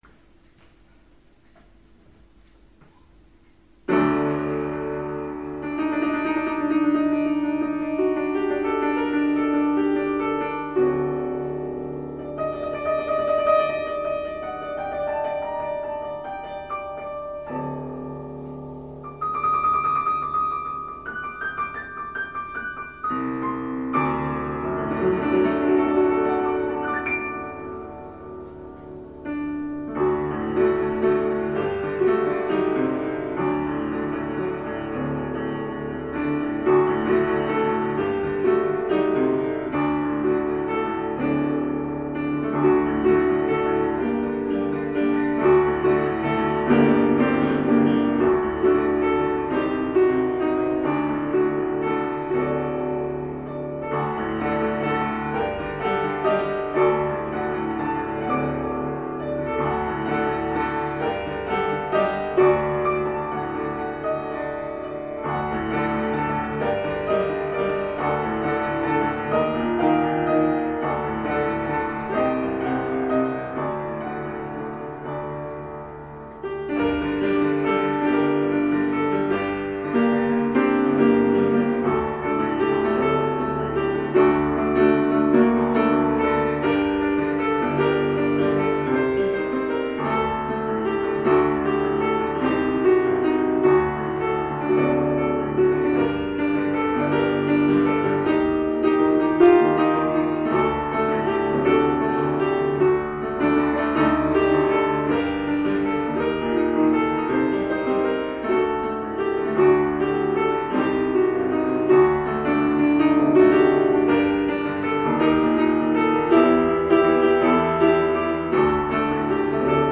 הסוף היה קצת חד...